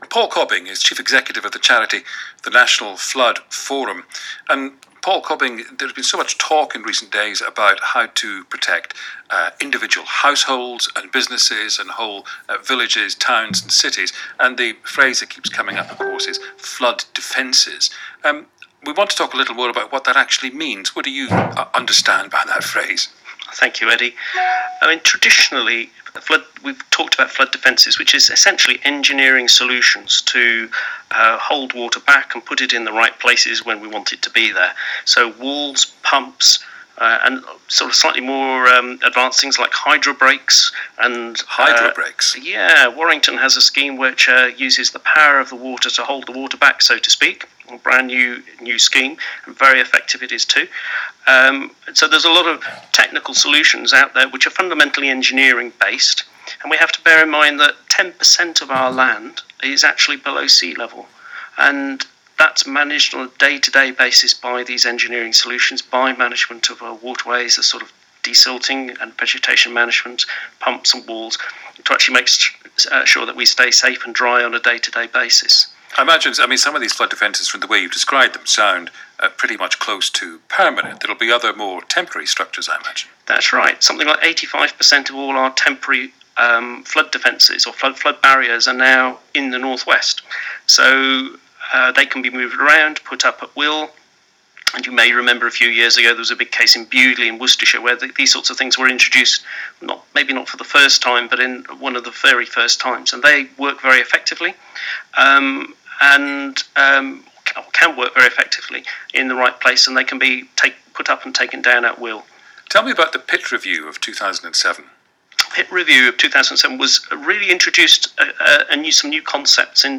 Posted December 29, 2015 & filed under Blog, Community Resilience, Flood Mitigation, Media Interviews.